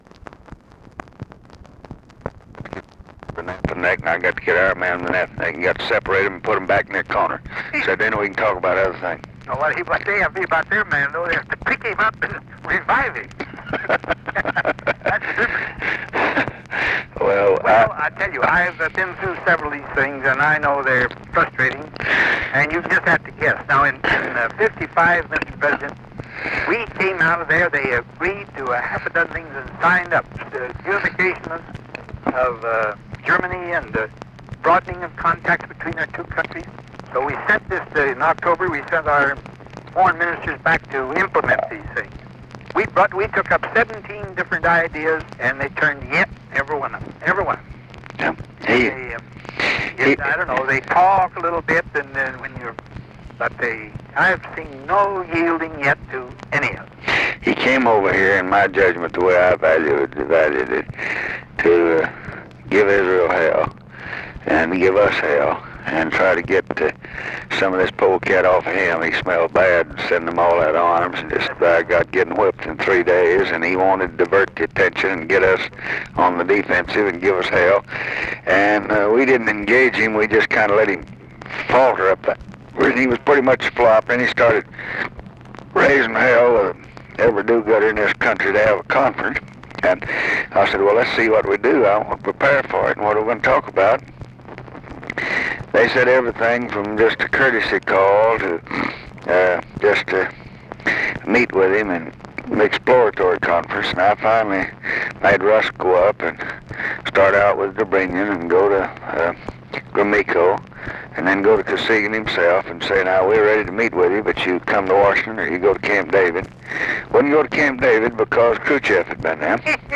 Conversation with DWIGHT EISENHOWER, June 26, 1967
Secret White House Tapes